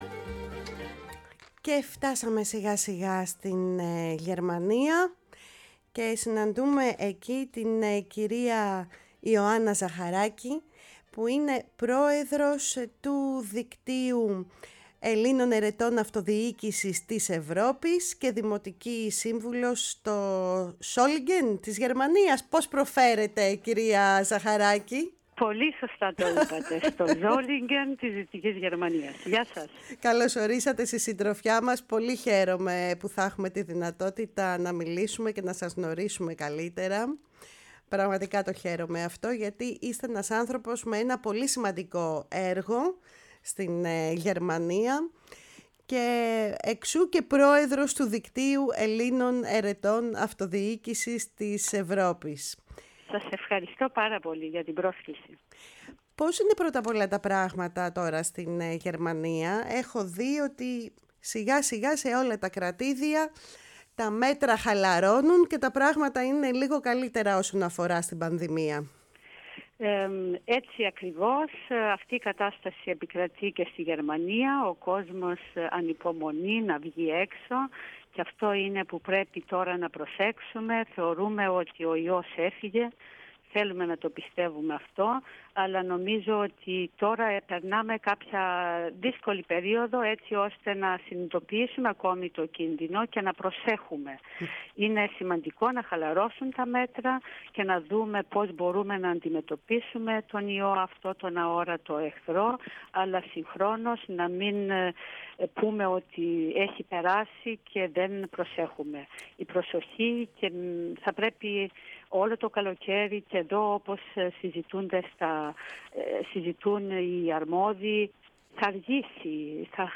Η πρόεδρος του Δικτύου Ελλήνων Αιρετών Αυτοδιοίκησης της Ευρώπης, κυρία Ιωάννα Ζαχαράκη, φιλοξενήθηκε στη “Φωνή της Ελλάδας” και συγκεκριμένα στην εκπομπή “Κουβέντες μακρινές”